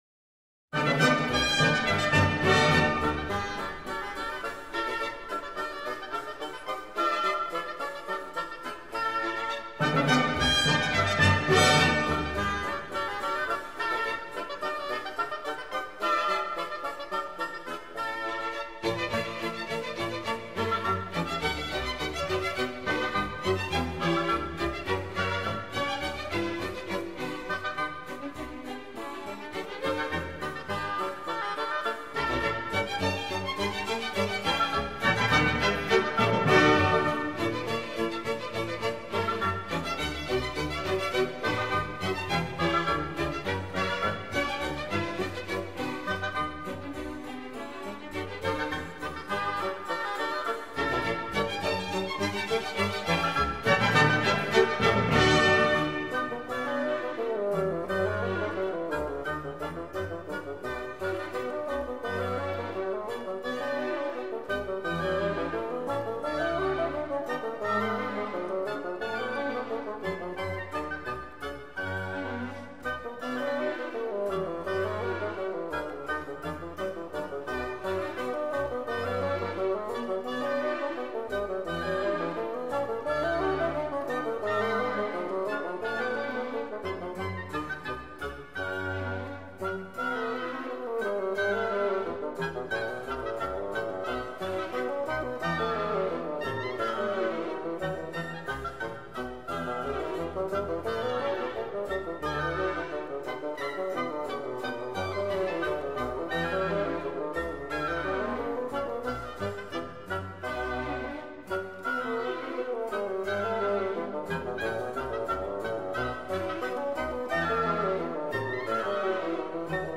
Glenn Gould, piano